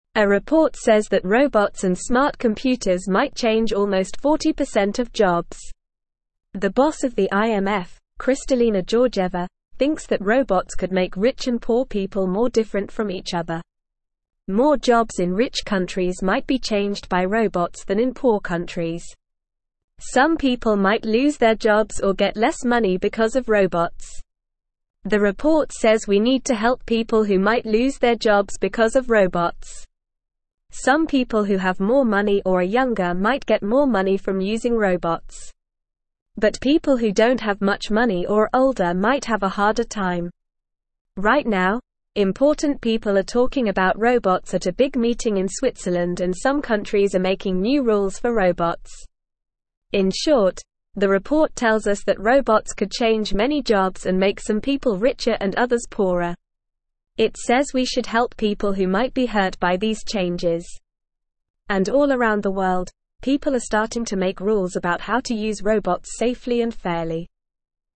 Normal
English-Newsroom-Lower-Intermediate-NORMAL-Reading-Smart-computer-programs-could-change-many-jobs.mp3